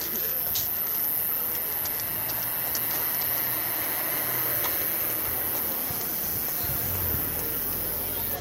描述：破碎的萨克斯管音符声音样本.Amostra de som de nota de saxofone ruim，quebrado，barulho。
Tag: 大气 AMBIENTE 音景 一般噪音 氛围 夜晚 背景 背景声 街道 晚报 氛围 白噪声 板球 公园 索姆 现场记录 环境 Grilo的 城市